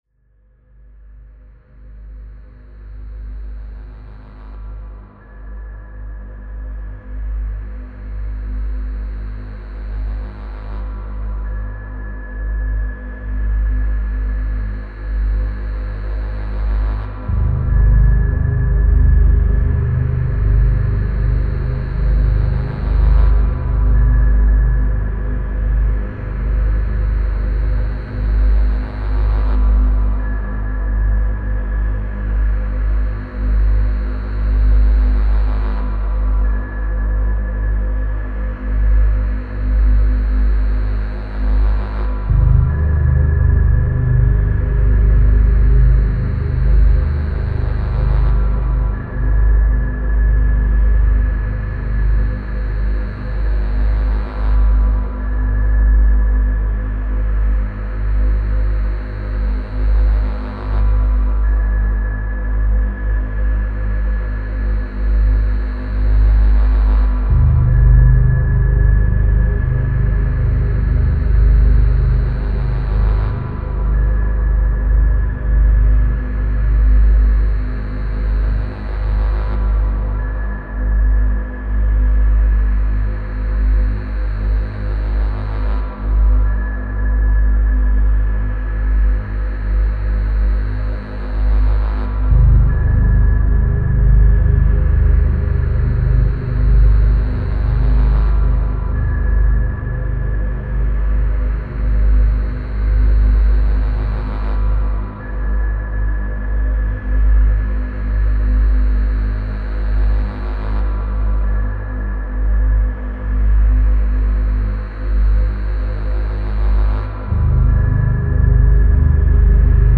Бездонная пропасть Дарк-Эмбиента (Накручиваем лютый дарк-эмбиент)